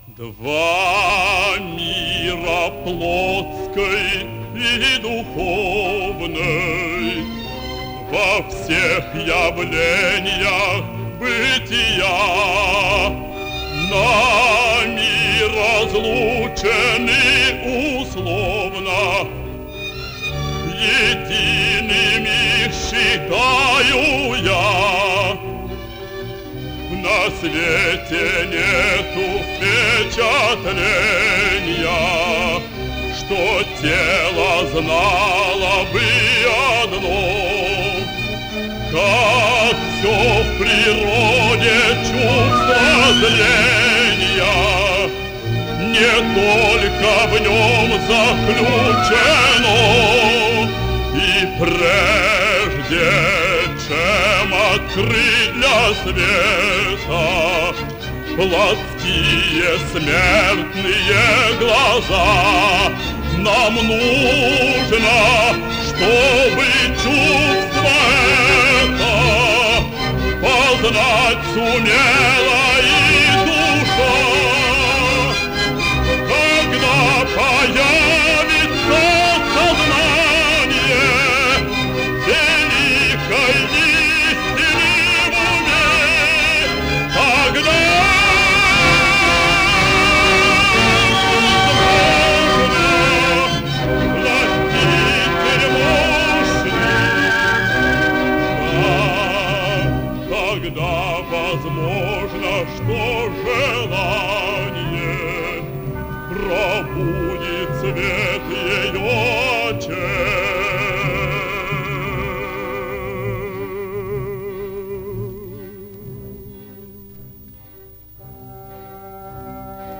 11 - П.И.Чайковский. Иоланта - Монолог Эбн-Хакиа (Александр Батурин) (1940)